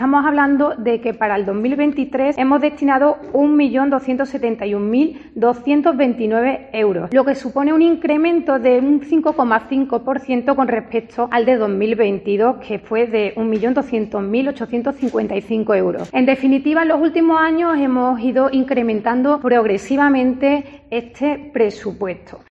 En una rueda de prensa, la edil ha resaltado que se aportan más de 1,2 millones de euros en ayudas para cuatro convocatorias, un incremento de un 5,5% sobre 2022, a la vez que ha elogiado que "el de Córdoba es el ayuntamiento a nivel andaluz que destina mayor presupuesto a la Cooperación Internacional".